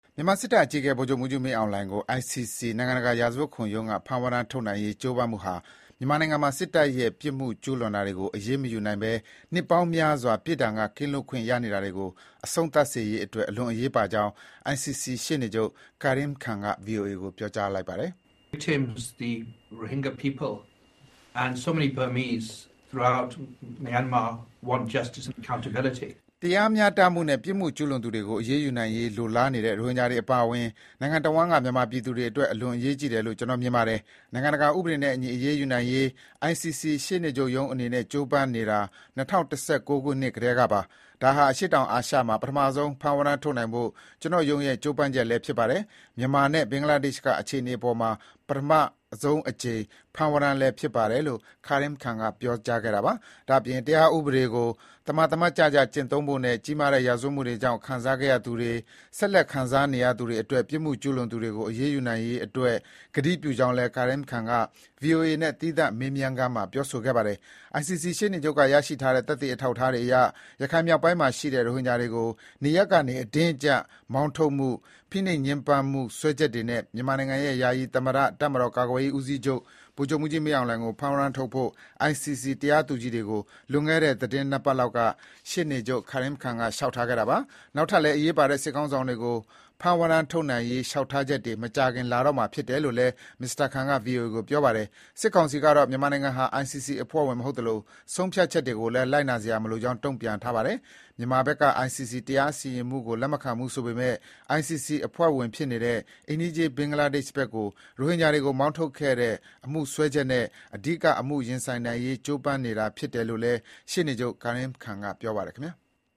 ICC ရှေ့နေချုပ် Karim Khan
ဒါ့အပြင် တရားဥပဒေကို သမာသမတ်ကျကျ ကျင့်သုံးမှာဖြစ်ပြီး ကြီးလေးတဲ့ရာဇဝတ်မှုတွေကြောင့် ခံစားခဲ့ရသူတွေ၊ ဆက်လက်ခံစားနေရသူတွေအတွက် ပြစ်မှုကျူးလွန်သူတွေကို အရေးယူနိုင်ရေး အတွက်လည်း ကတိပြုကြောင်း” Mr. Khan က ဗွီအိုအေနဲ့ သီးသန့်မေးမြန်းခန်းမှာ ပြောဆိုခဲ့ပါတယ်။